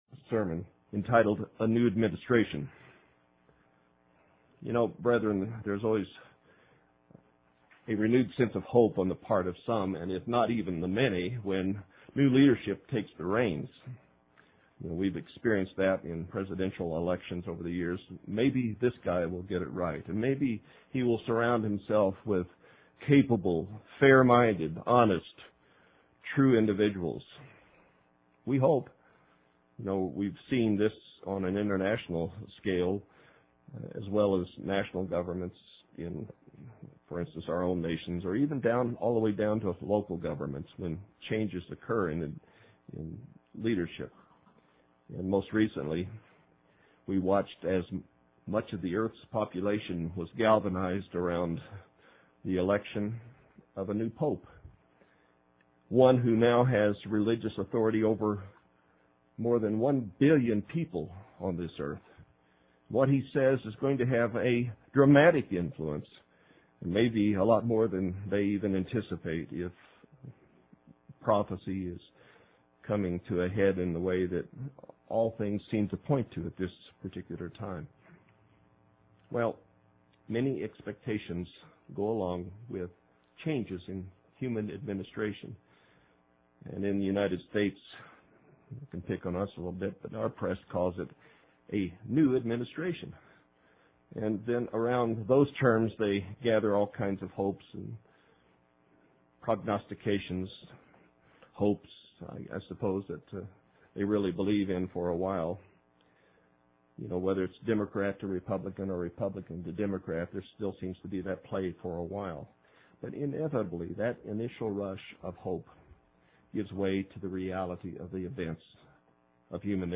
Sermons – Page 224 – Church of the Eternal God